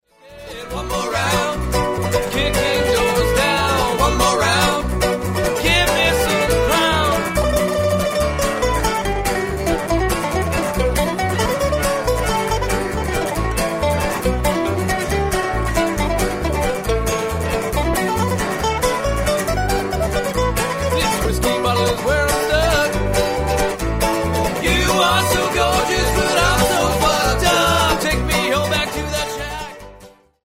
brazen punk rock song writing and lyrics.